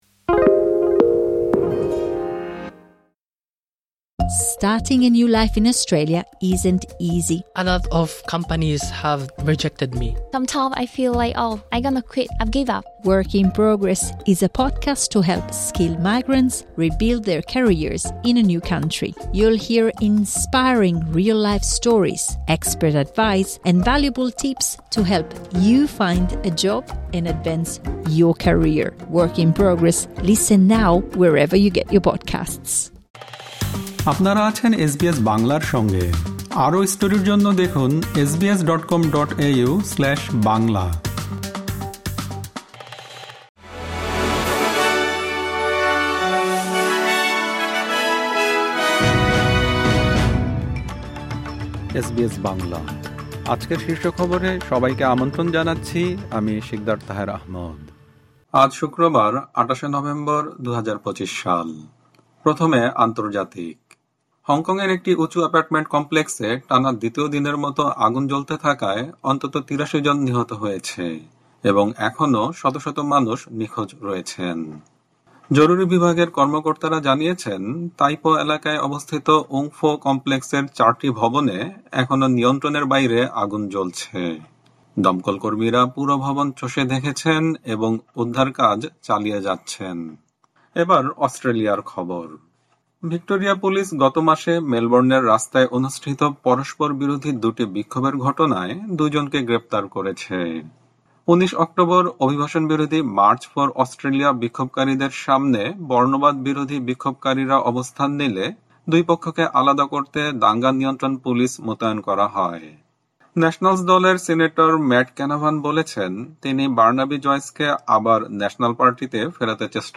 এসবিএস বাংলা শীর্ষ খবর: ২৮ নভেম্বর, ২০২৫